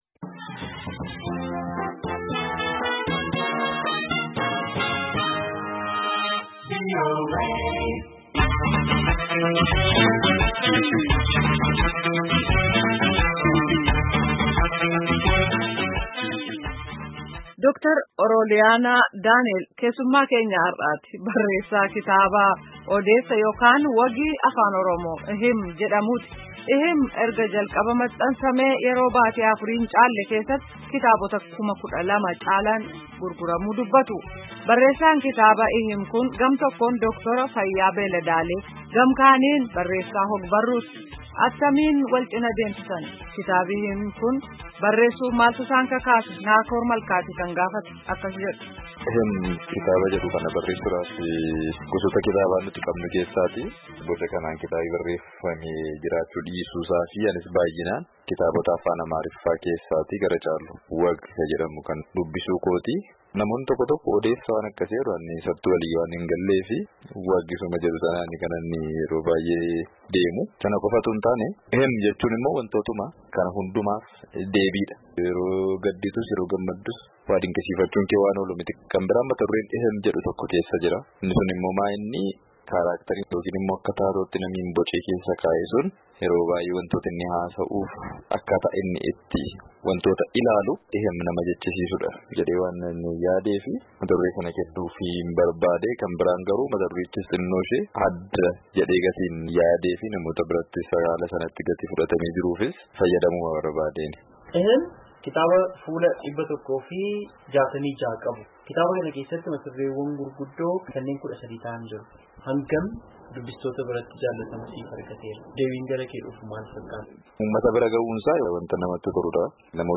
Gaaffii fi deebii